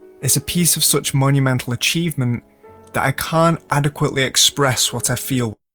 Vocal Sample